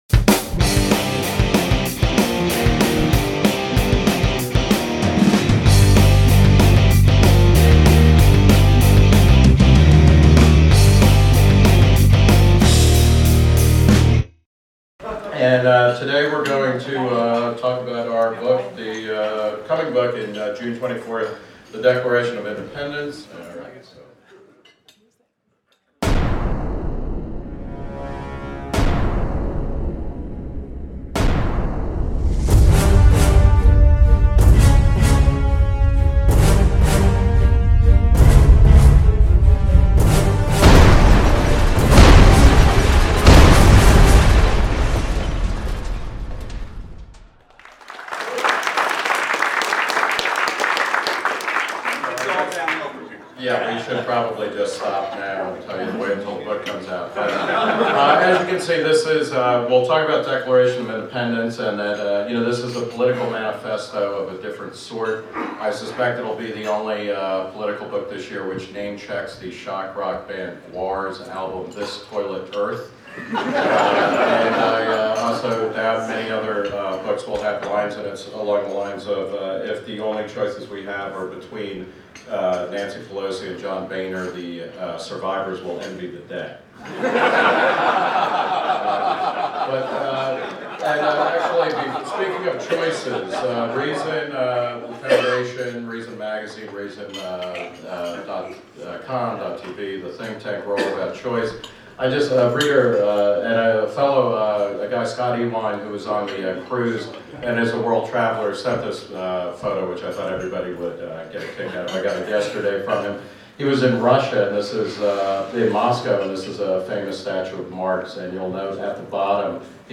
At Reason Weekend 2011, held earlier this year in Laguna Niguel, California, Reason's Matt Welch and Nick Gillespie previewed their forthcoming book The Declaration of Independents: How Libertarian Politics Can Fix What's Wrong With America.